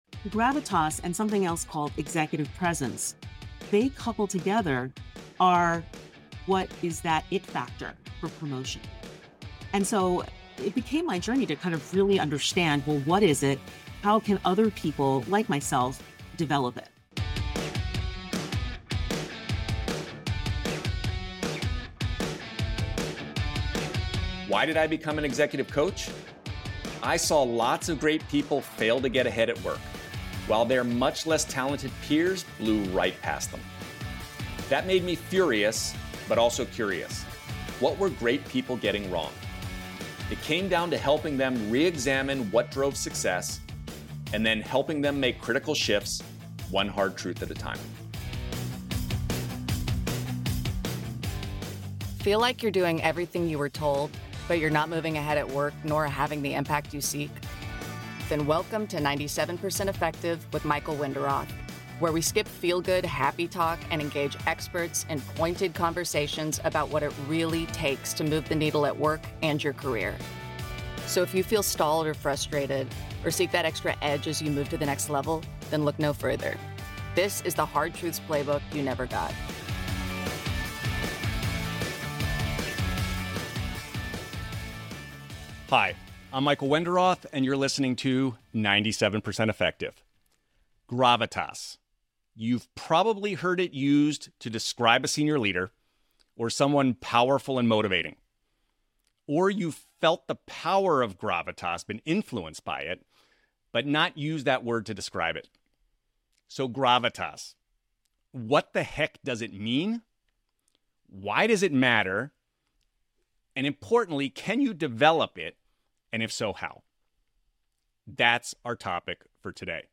Season #1 focuses on Power and Influence, two widely acknowledged (but poorly understood) forces that can help you rise, lead more effectively and get big things done. Each week we have candid conversations with an academic, coach, or executive, helping you gain new insights to better navigate your work and career.